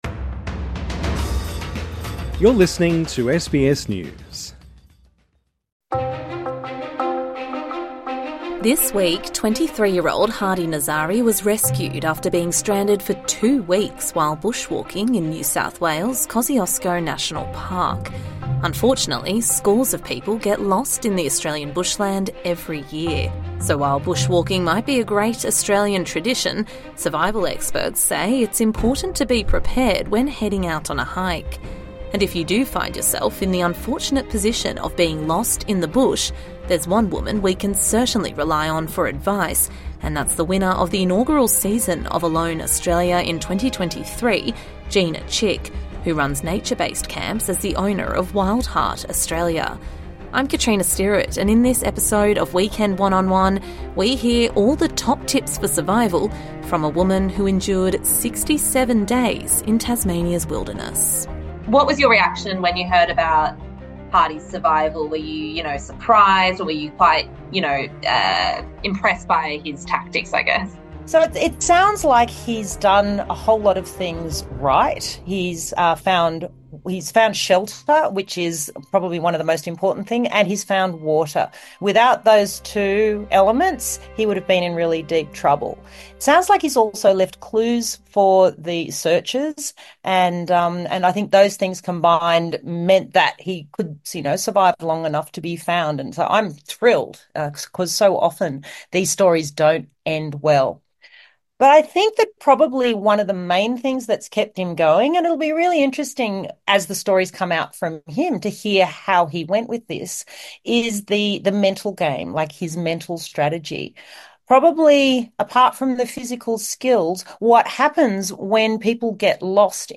INTERVIEW: Gina Chick on how to survive getting lost in the Australian bush